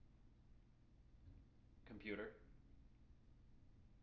wake-word
tng-computer-20.wav